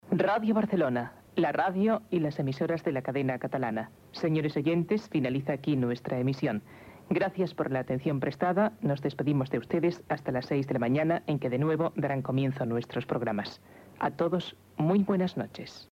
Tancament d'emissió de Ràdio Barcelona i de les emissores de la Cadena Catalana.